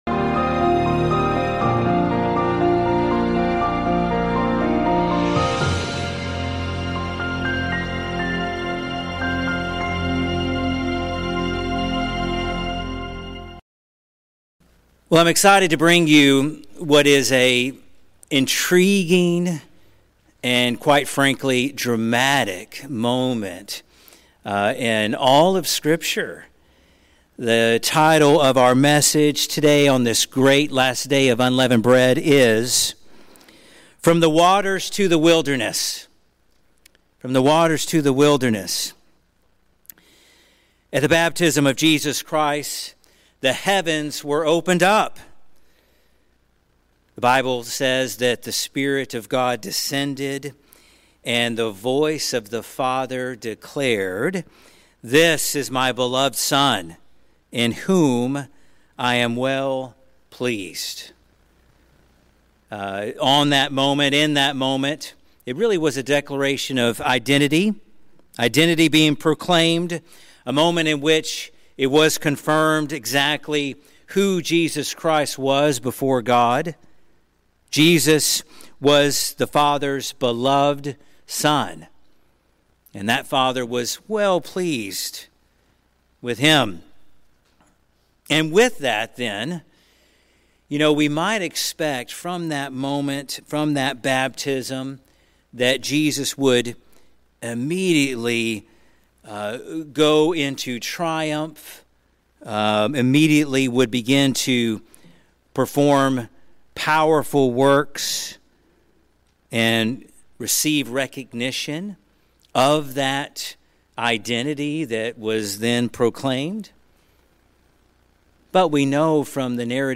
This sermon reminds us that just as Jesus was affirmed at His baptism and then tested in the wilderness, our commitment to God is often followed by trials that refine our faith and confirm our identity in Him. Through Christ’s victory over temptation, we are assured that even in our hardest moments, we can overcome and be found pleasing in His sight.